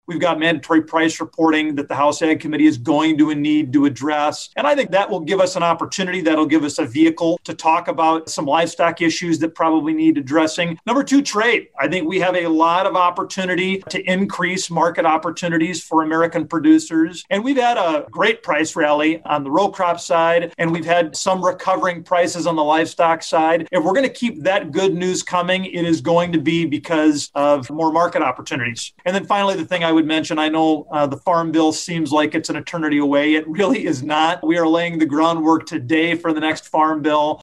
Agri-Pulse recently held a webinar to talk about the top issues facing rural America this year and what might be ahead in the 117th Congress for potential answers to those challenges.
One of the speakers was South Dakota Republican Congressman Dusty Johnson.